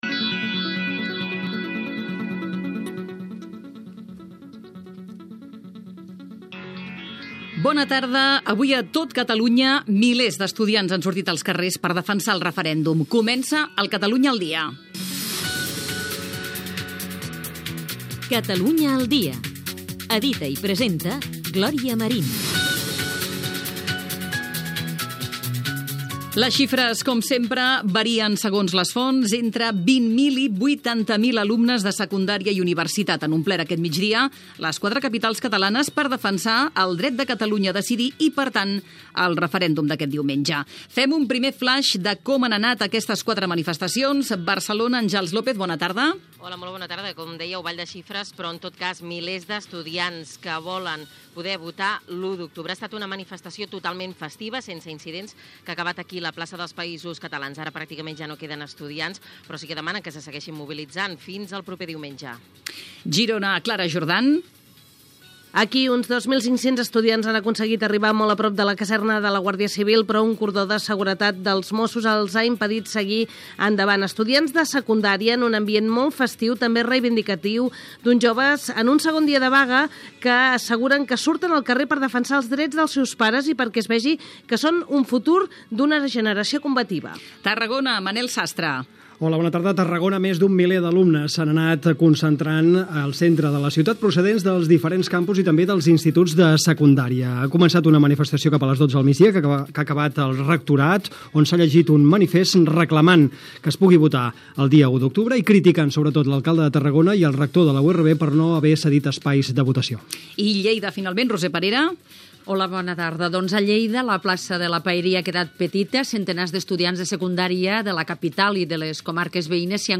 Indicatiu del programa, manifestacions dels estudiants en defensa del dret a votar en el Referèndum d'Autodeterminació de Catalunya de l'1 d'octubre, Connexions amb Barcelona, Tarragona, Lleida i Girona
Informatiu
FM